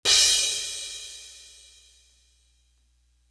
Crashes & Cymbals
Bring You Light Crash.wav